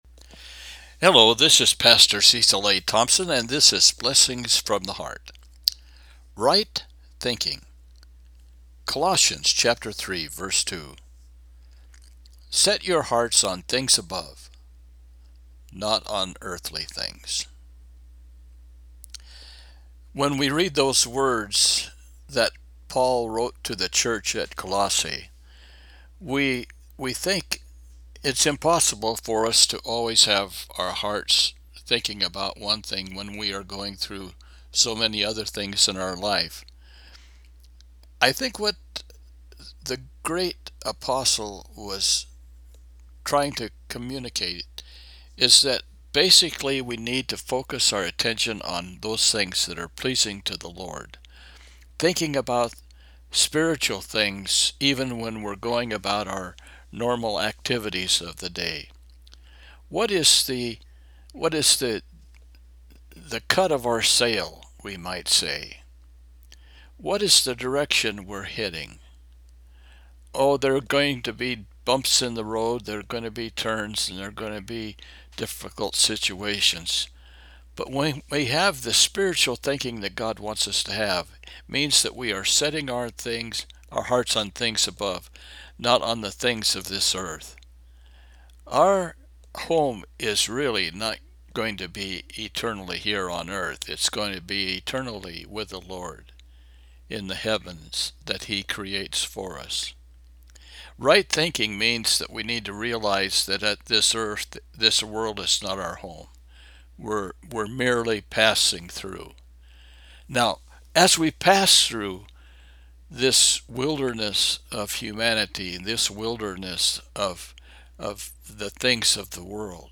Colossians 3:2 – Devotional